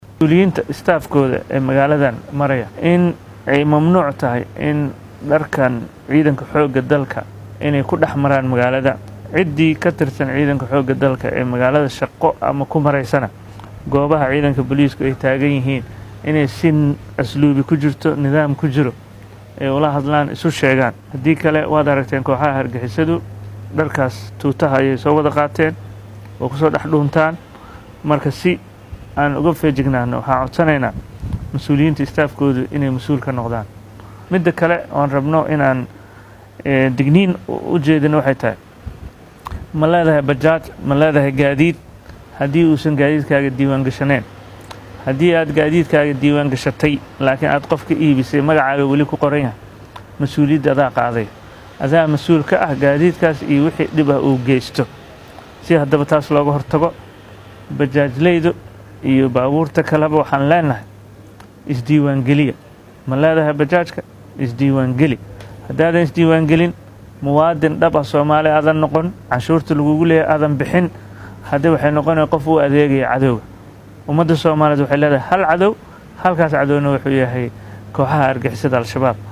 Guddoomiye Yariisow oo la hadlay Warbaahinta shirka marka uu soo idlaaday ayaa ka warbixiyay go’aannada ka soo baxay, waxaana ay sheegay in ay dhaqan gelin doonaan.